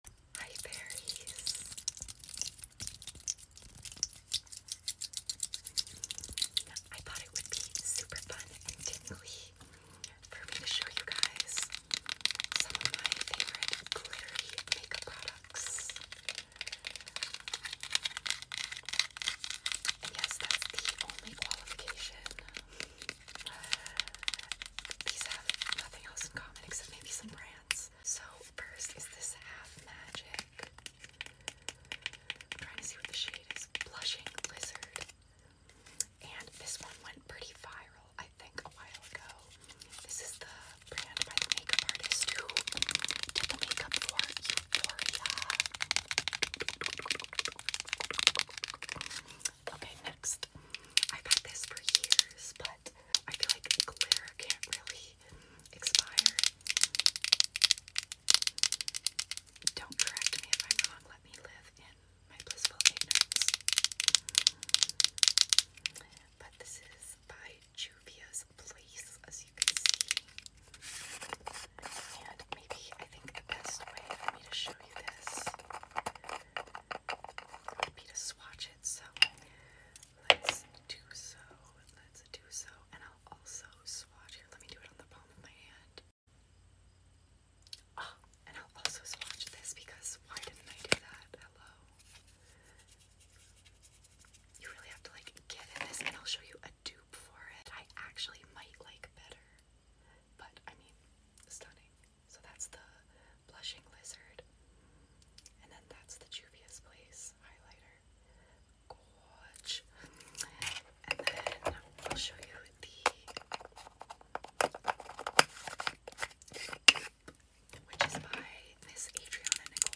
7 Min Of Tapping And Sound Effects Free Download